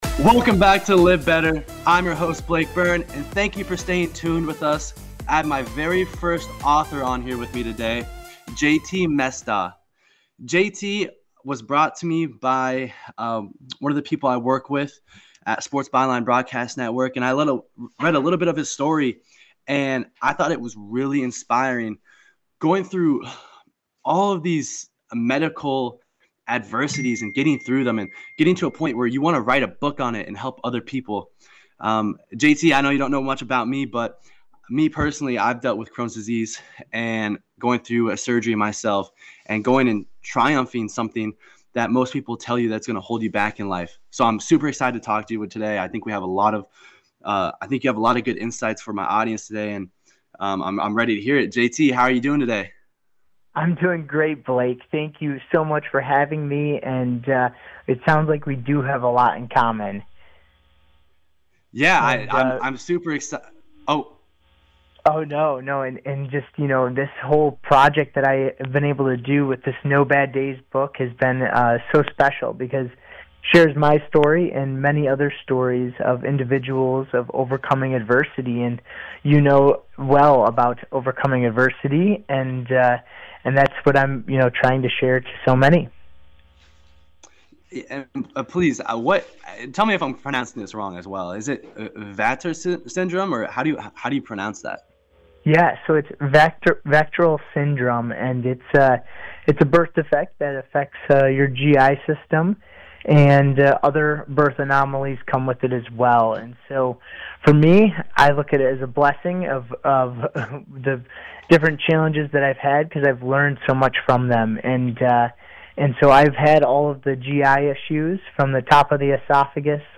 Link to interview